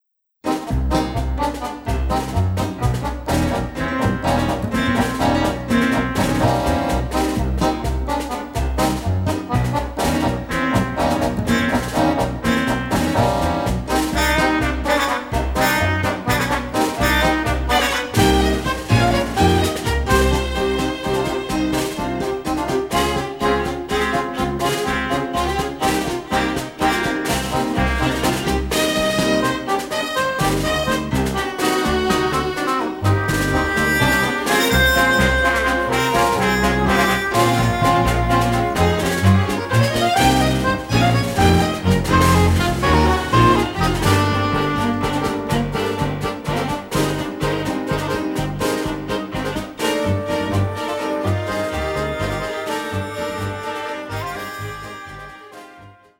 Recorded in London
short dynamic and rythmical score